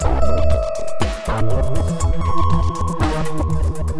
The stalker (Full) 120BPM.wav